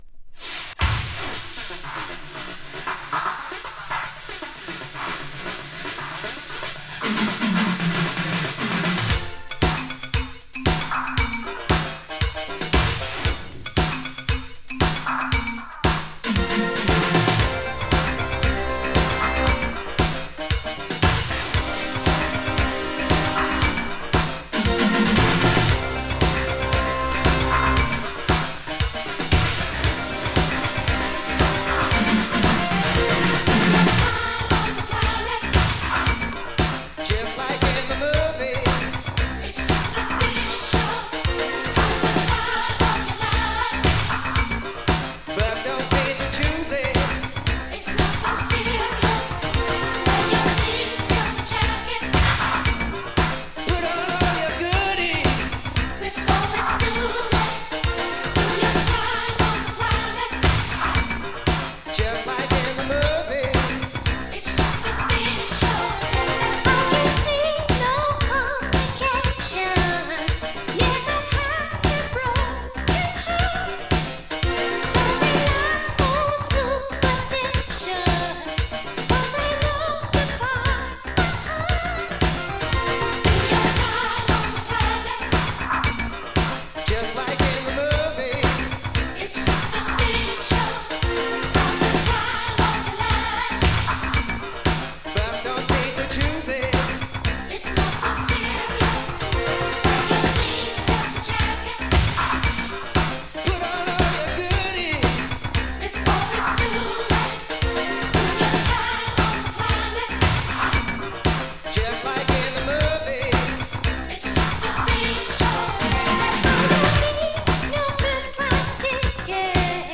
[CANADA] [Hi-NRG]
High energy disco from Canada!
melancholy high energy disco